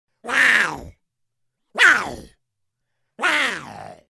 Дикая кошка